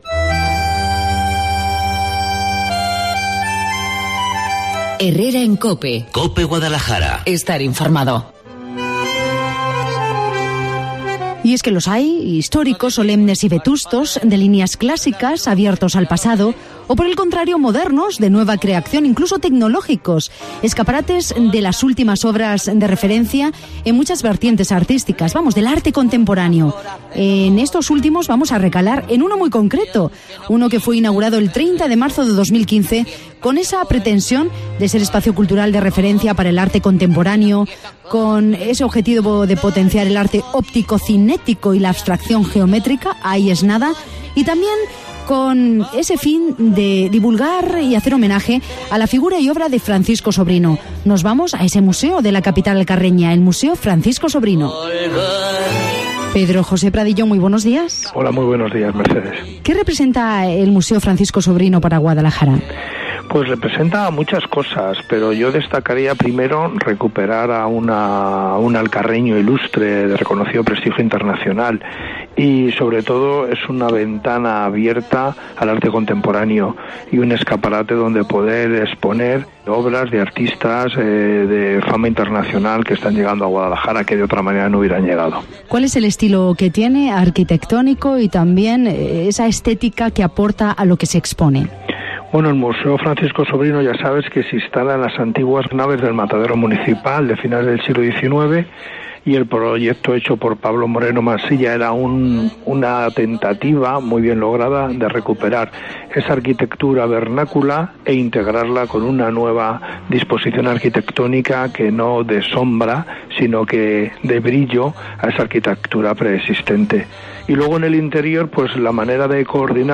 En el Día Internacional de los Museos, visitamos el Francisco Sobrino de Guadalajara. Instalado en las antiguas naves del Matadero Municipal, este espacio museístico fue inaugurado el 30 de marzo de 2015 con la intención de servir de escaparate de referencia del arte contemporáneo y divulgar la figura y obra de Francisco Sobrino.Precisamente, desde hoy hasta el 30 de mayo, el museo alcarreño acoge una exposición sobre el artista centrándose en su vertiente de emigrante y la influencia del arte andalusí en su obra.